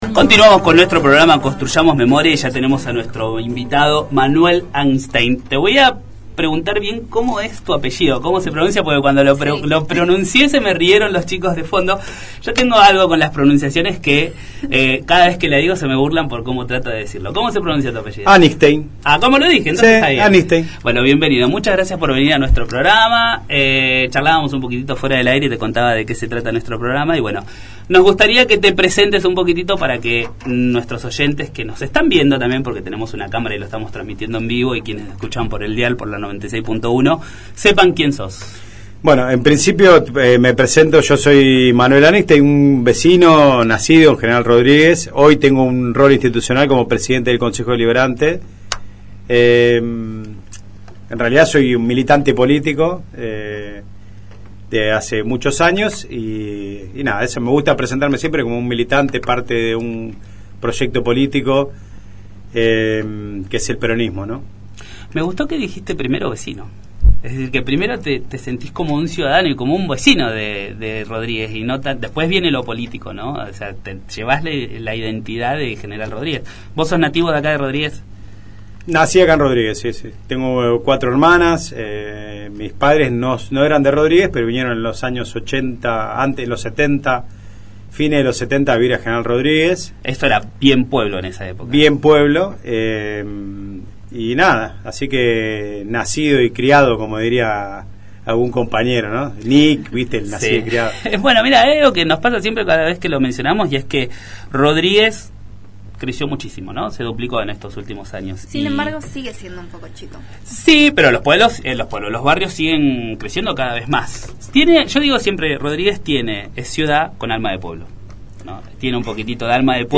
Entrevista a Manuel Anigstein